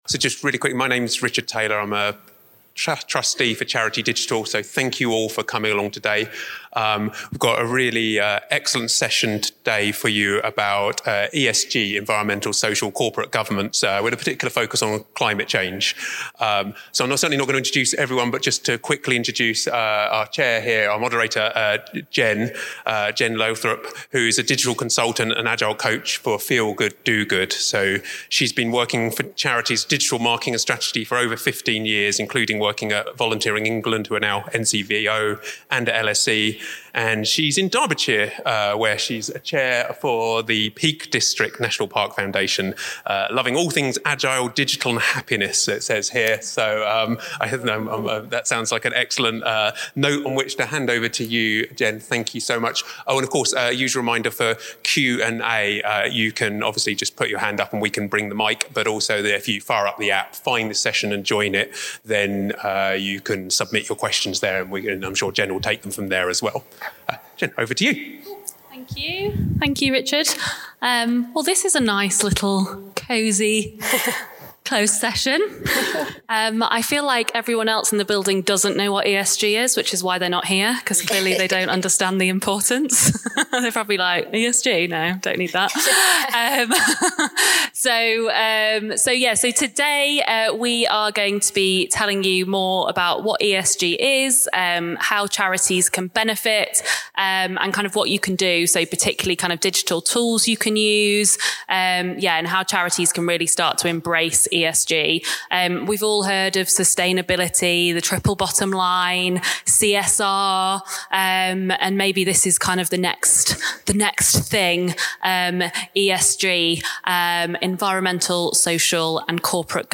It’s time for charities to build environmental, social, and governance (ESG) strategies into day-to-day work. Our panel of experts discuss ESG and consider how charities can build it into strategies so they can implement meaningful changes. The session, taken from a recording from our 2023 Conference